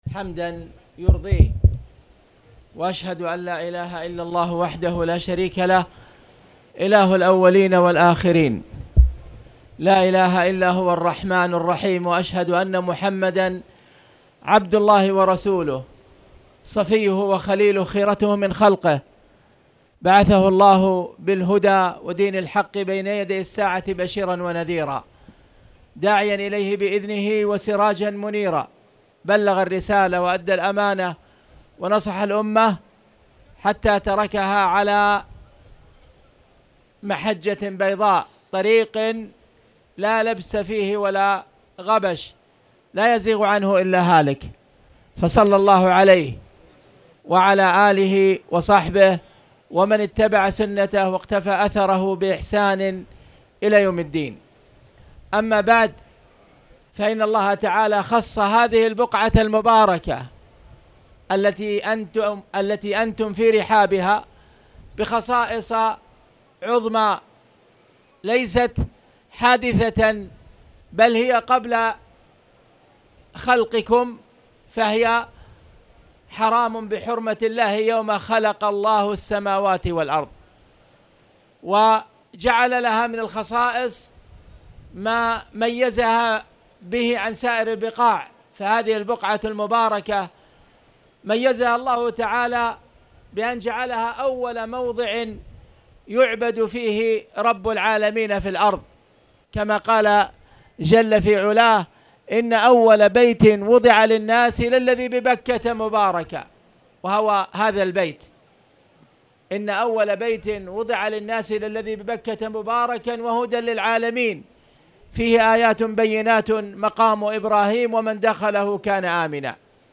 تاريخ النشر ٣ ربيع الأول ١٤٣٨ هـ المكان: المسجد الحرام الشيخ